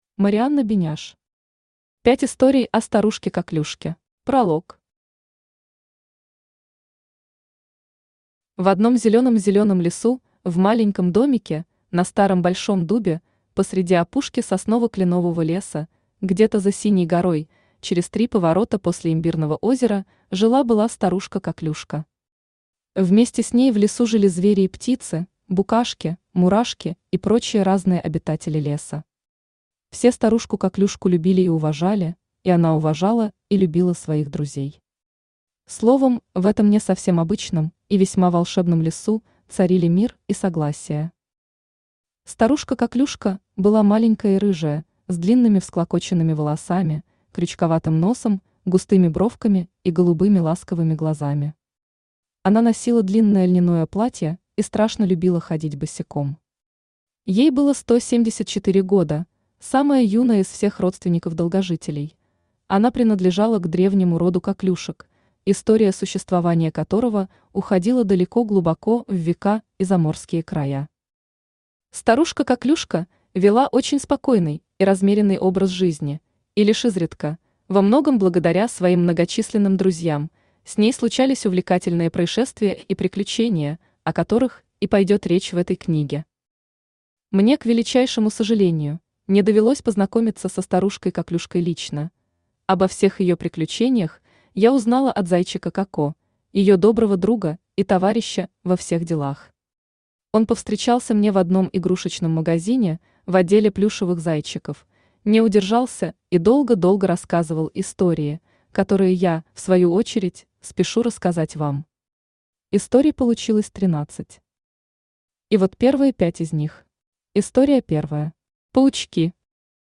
Аудиокнига 5 историй о Старушке Коклюшке | Библиотека аудиокниг
Aудиокнига 5 историй о Старушке Коклюшке Автор Марианна Беняш Читает аудиокнигу Авточтец ЛитРес.